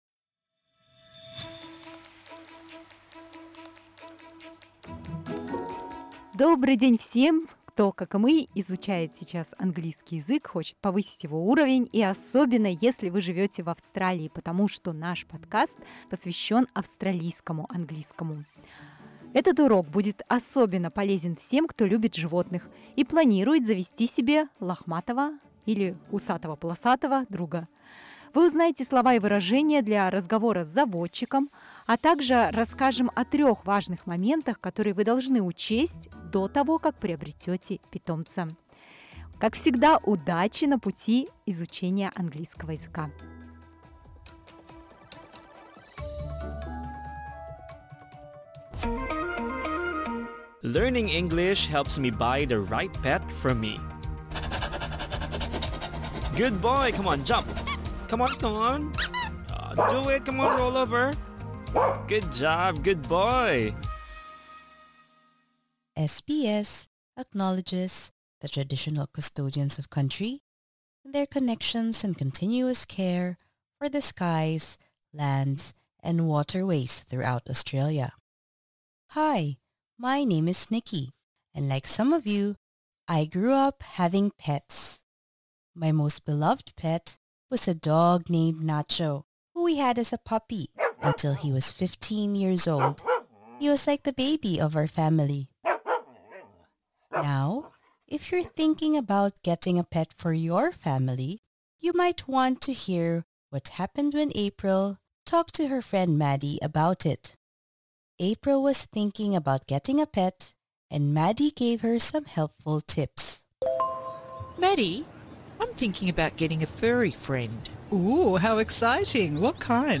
This lesson suits intermediate learners.